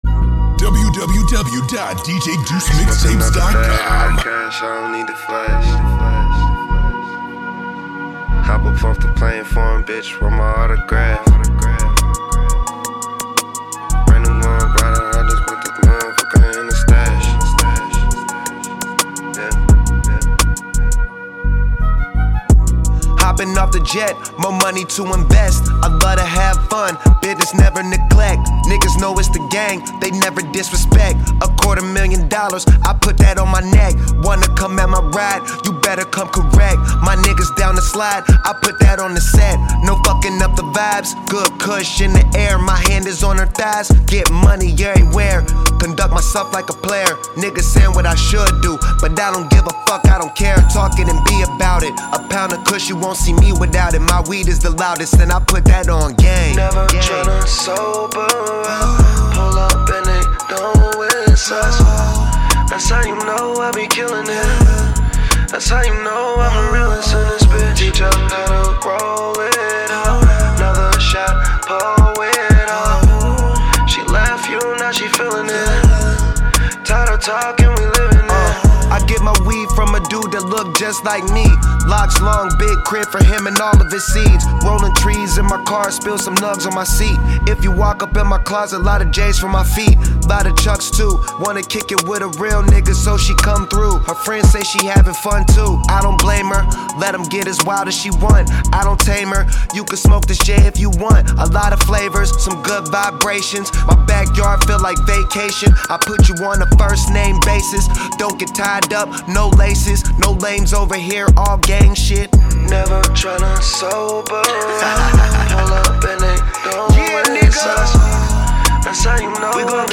mixed version